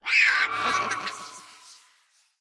Minecraft Version Minecraft Version snapshot Latest Release | Latest Snapshot snapshot / assets / minecraft / sounds / mob / vex / charge3.ogg Compare With Compare With Latest Release | Latest Snapshot